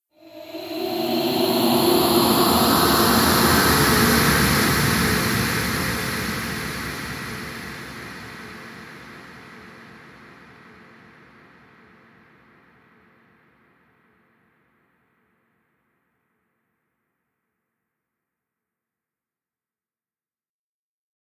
Призраки летают
prizraki_letayut_9et.mp3